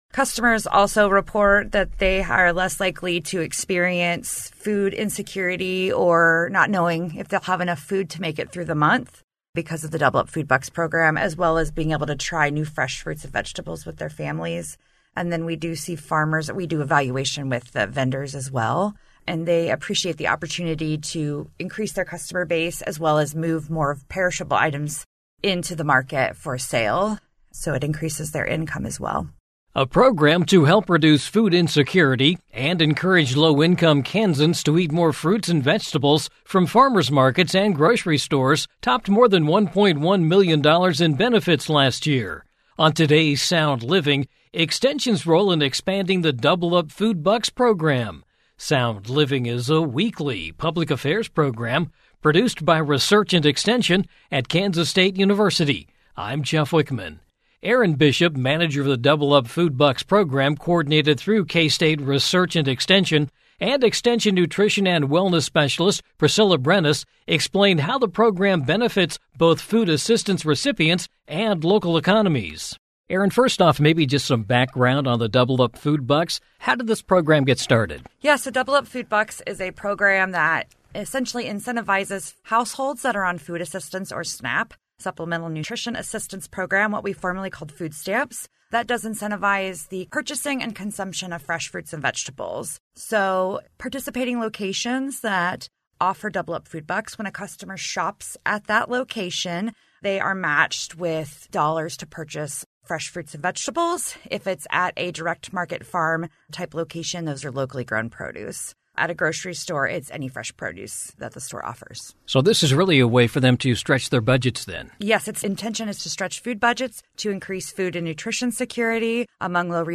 Sound Living is a weekly public affairs program addressing issues related to families and consumers.